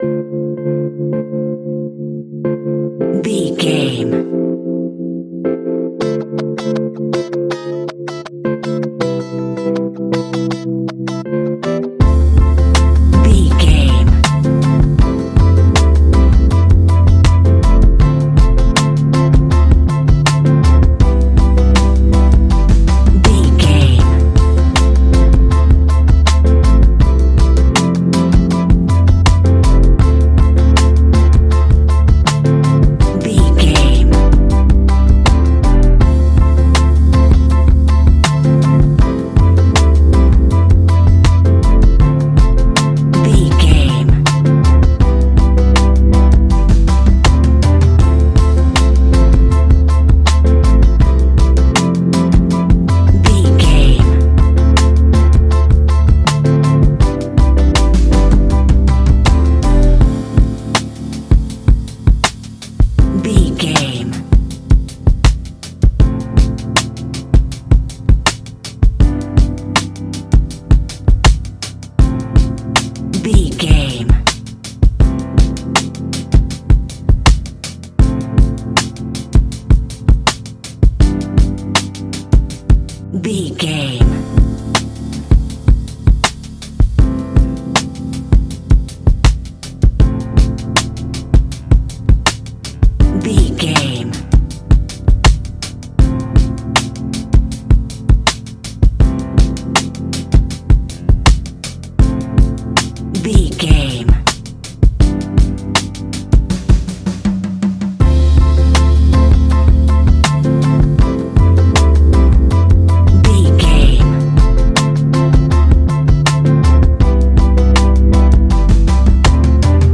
Ionian/Major
laid back
Lounge
sparse
new age
chilled electronica
ambient
atmospheric